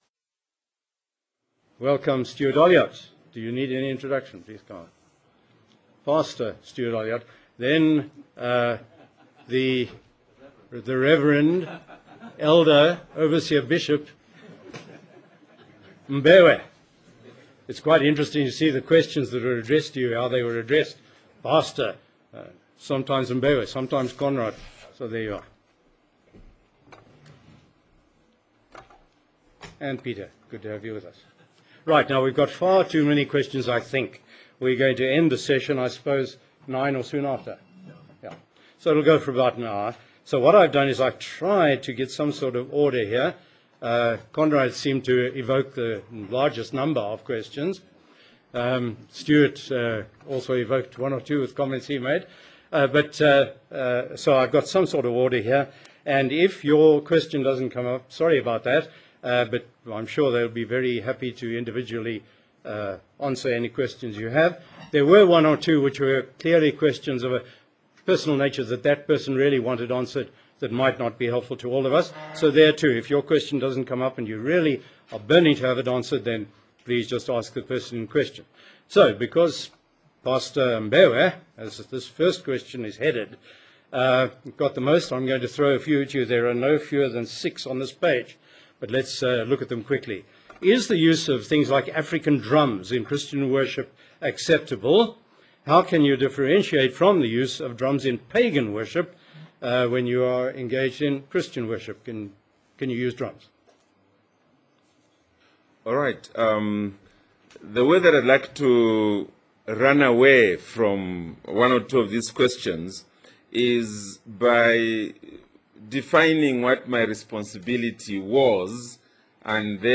2009 Q&A Session